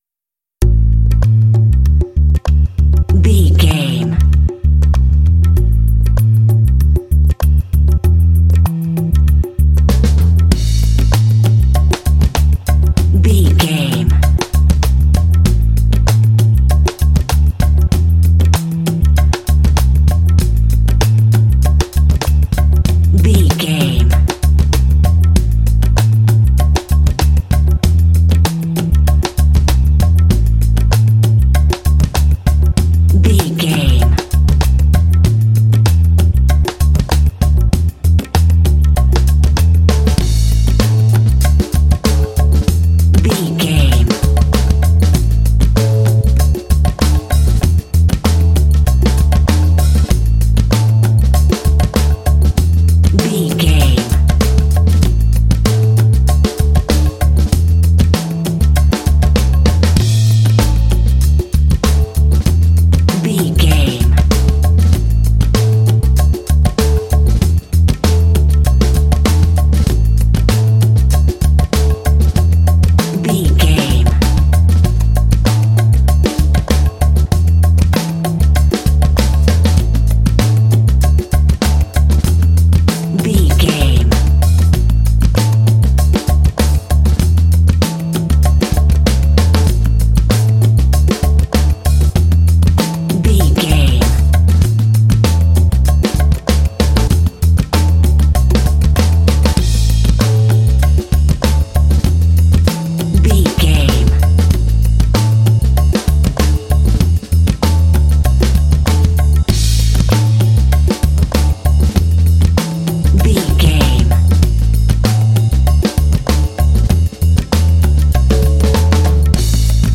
Aeolian/Minor
funky
smooth
bass guitar
percussion
drums
Funk
downtempo